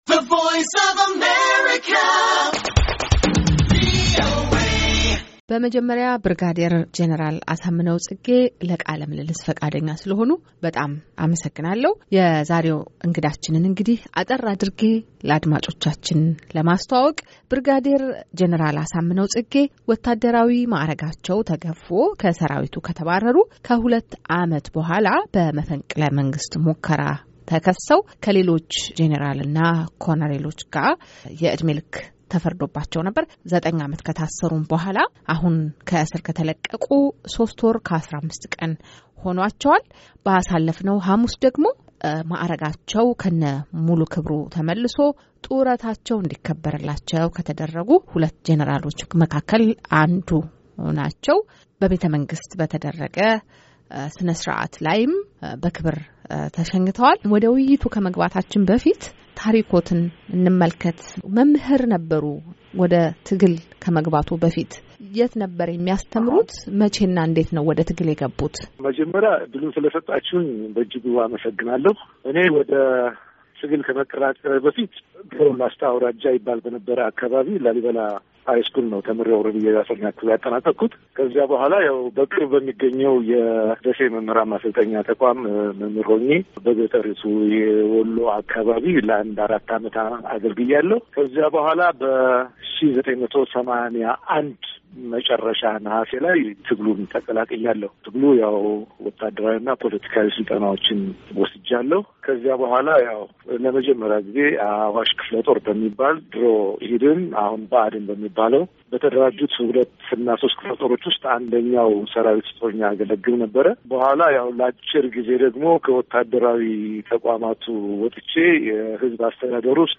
ከቀድሞው የመከላከያ ዩኒቨርስቲ ብሪጋዲየር ጄኔራል አሳምነው ፅጌ ጋራ የተደረገ ቃለ ምልልስ።